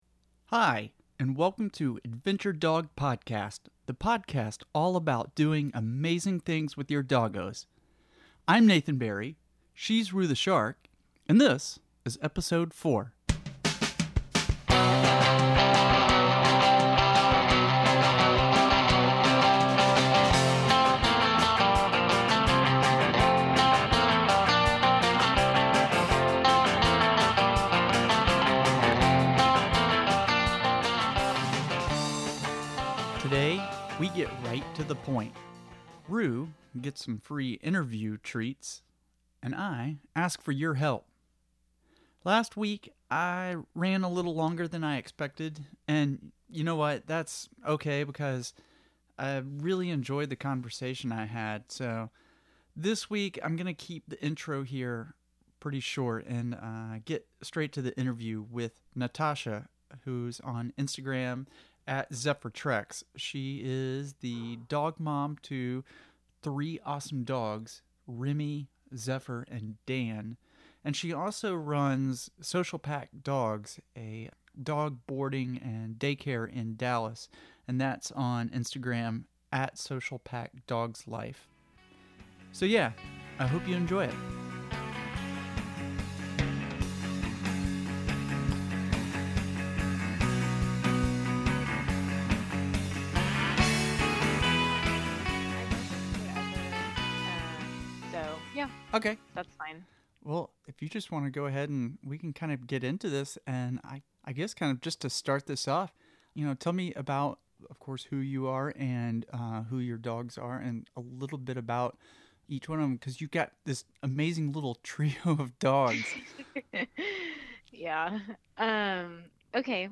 Today's interview